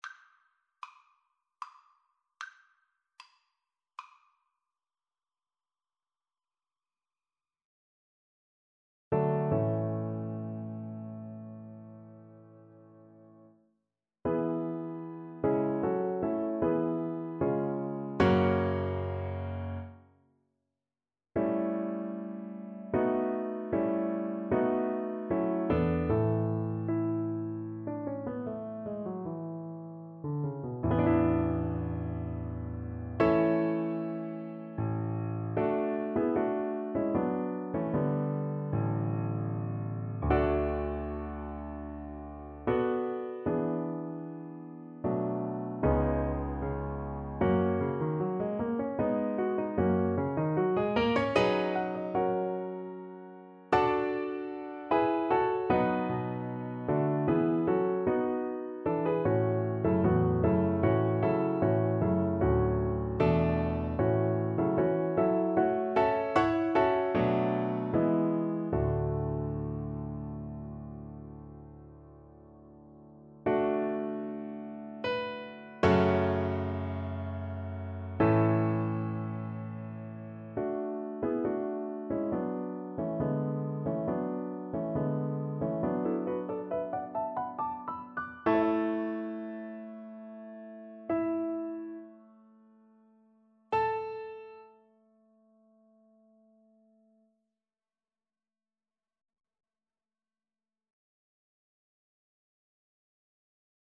• Unlimited playalong tracks
Trs calme et doucement expressif =76
Classical (View more Classical Violin Music)